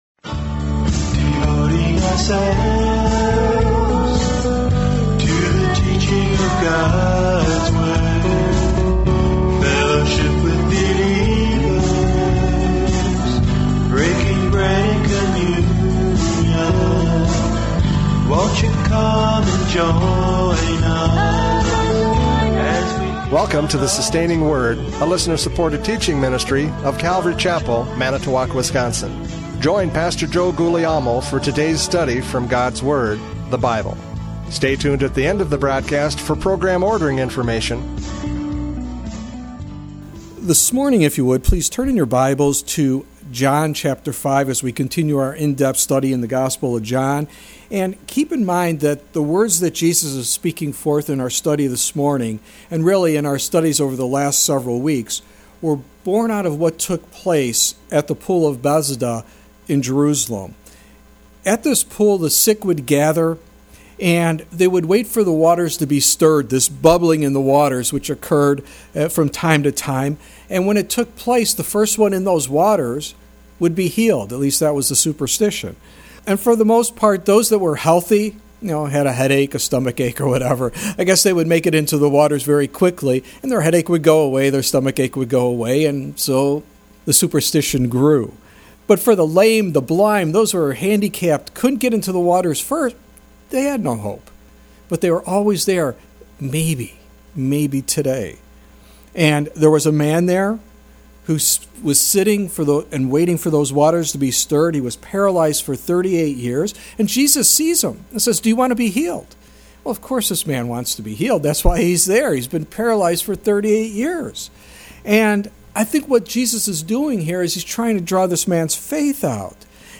John 5:36 Service Type: Radio Programs « John 5:31-35 Testimony of John the Baptist!